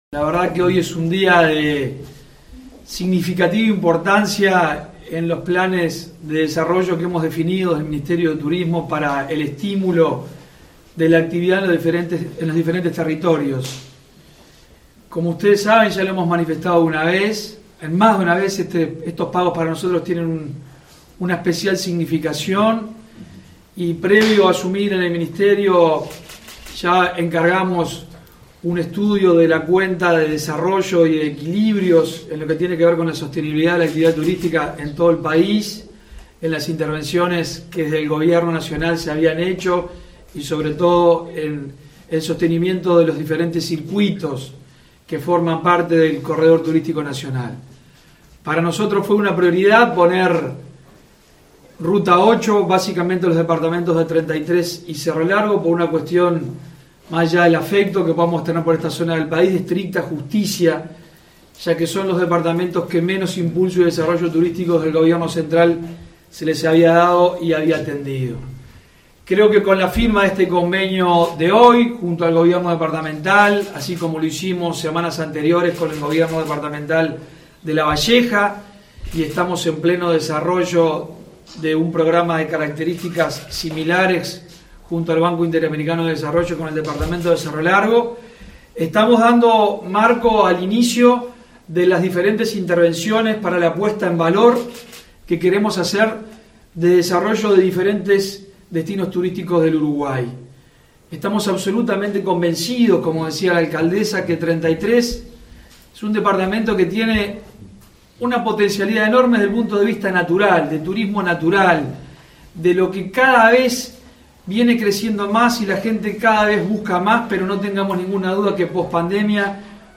Declaraciones del ministro de Turismo, Germán Cardoso
Cardoso participó, en La Charqueada, en la firma del convenio entre el Ministerio de Turismo y la Intendencia de Treinta y Tres, este lunes 16.